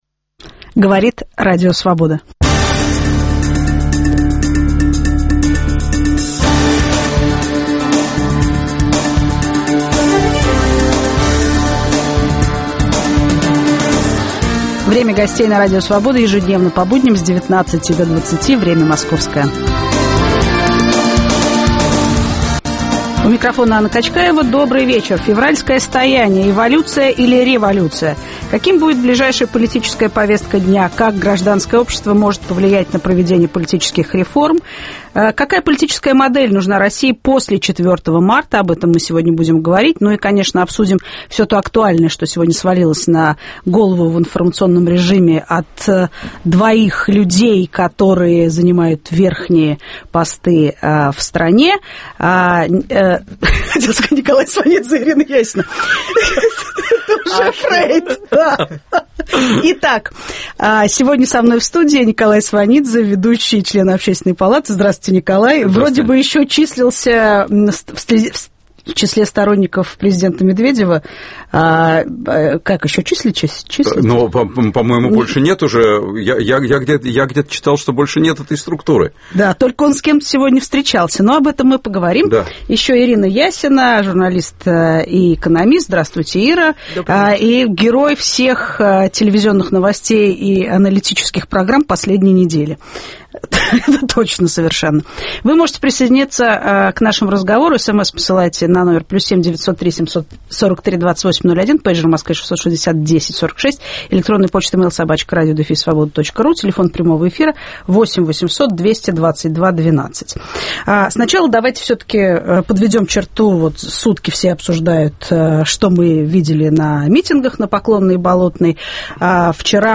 Как гражданское общество может повлиять на проведение политических реформ? Какая политическая модель нужна России после 4 марта? В студии экономист Ирина Ясина, член Общественной палаты, историк и телеведущий Николай Сванидзе.